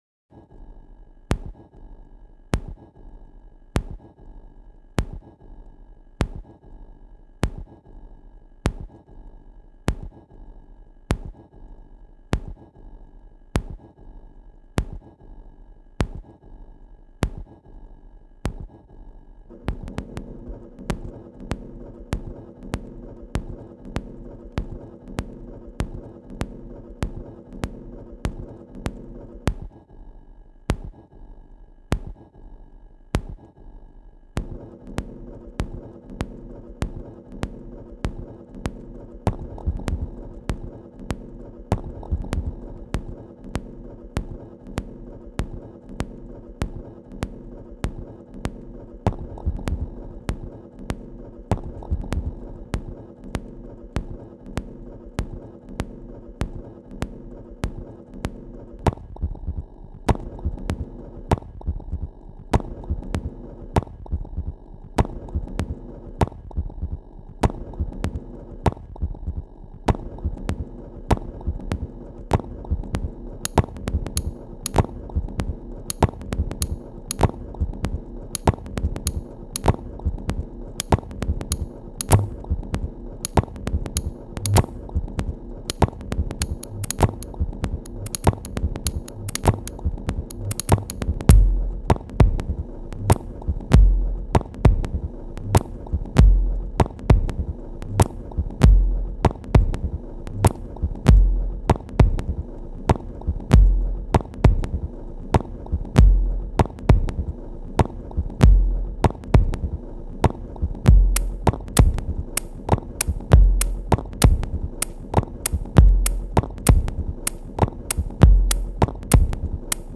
la dance music est aride